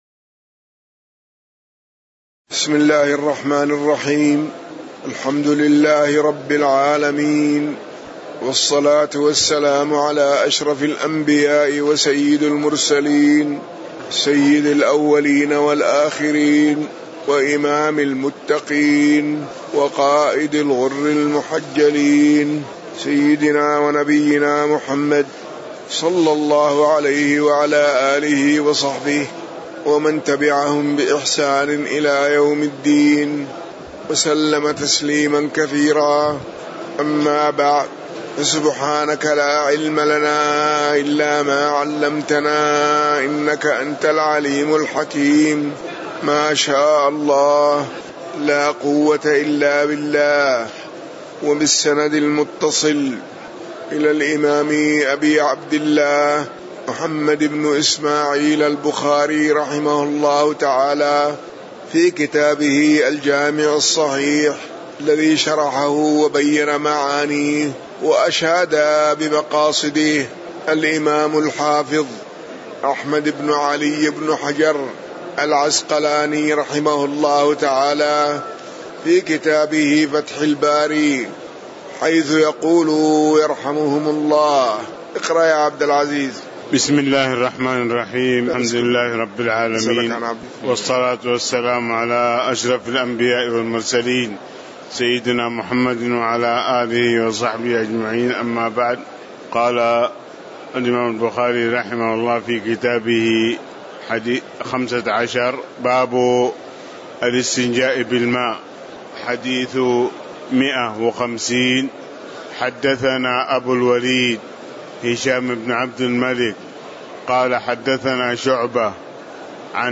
تاريخ النشر ١٠ ذو القعدة ١٤٣٩ هـ المكان: المسجد النبوي الشيخ